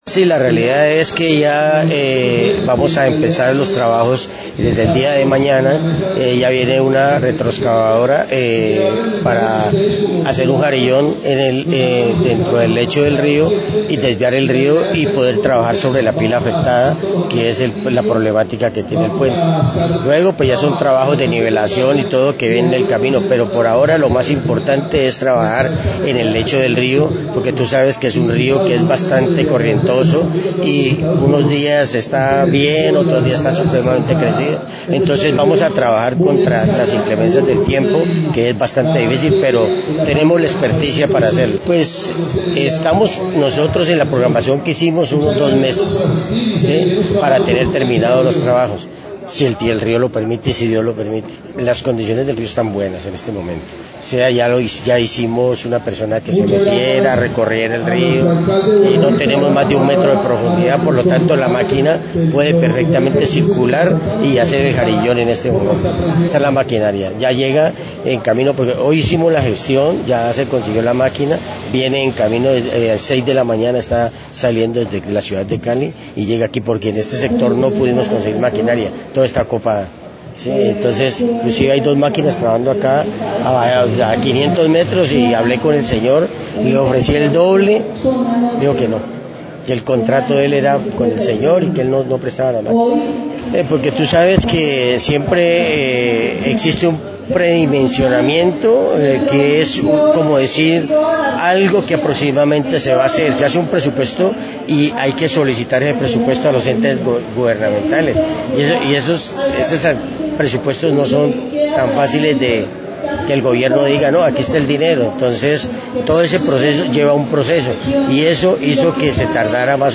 En sesión descentralizada de la honorable Asamblea Departamental, ampliada con líderes comunales y la veeduría cordillerana, se anunció que este viernes 14 de abril se iniciarán las labores de intervención en el puente de Barragán, con la expectativa de que en aproximadamente dos meses estén terminadas.